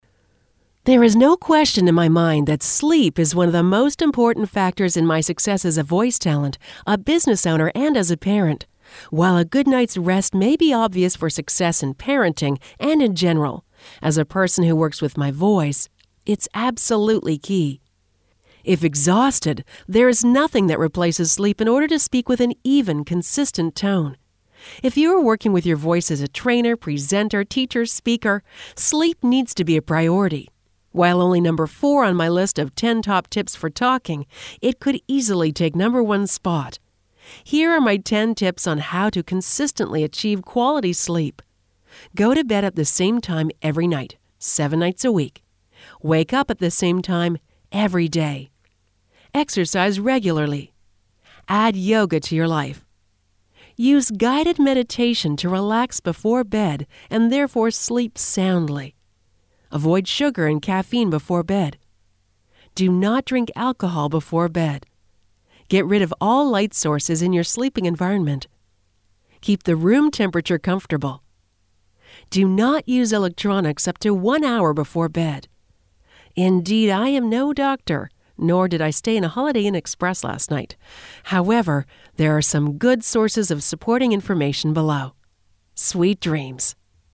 Sweet Dreams* (audio version of blog below) There is no question in my mind that SLEEP is one of the most important factors in my success as a voice talent, a business owner, and as a parent.